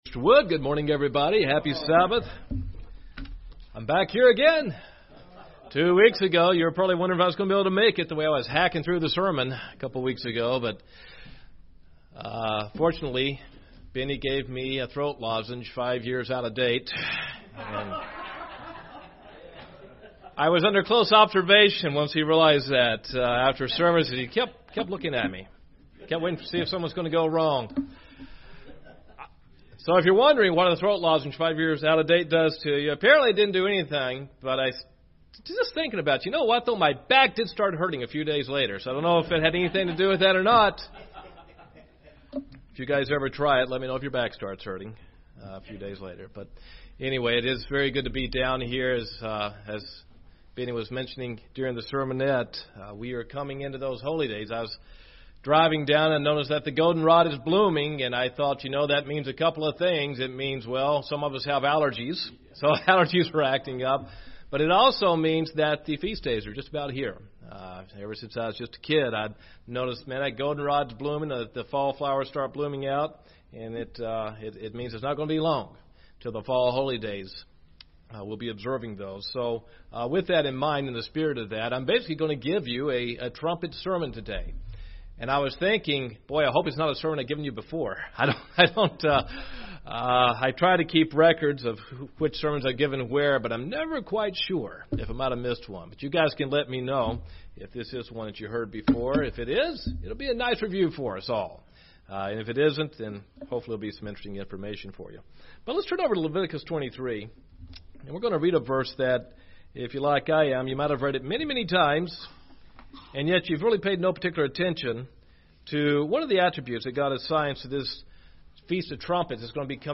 This sermon discusses four promises that God will remember when the Feast of Trumpets is fulfilled at the final trumpet blast.
Given in Gadsden, AL